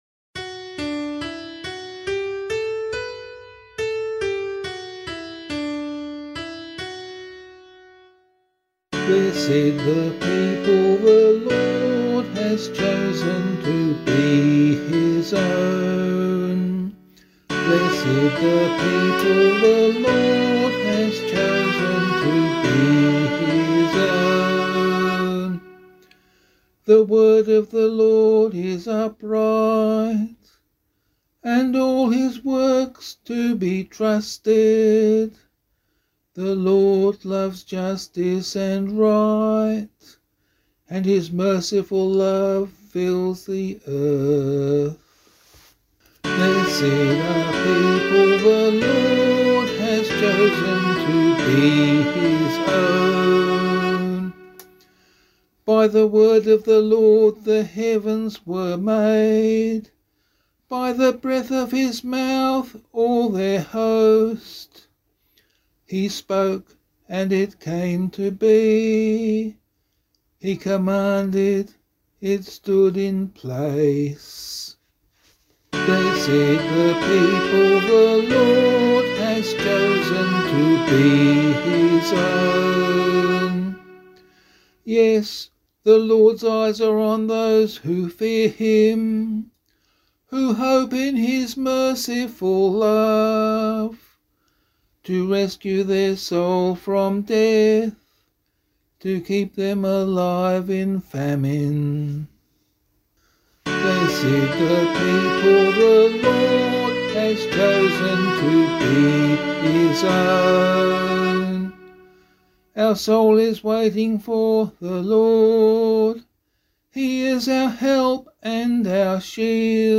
033 Trinity Psalm B [Abbey - LiturgyShare + Meinrad 5] - vocal.mp3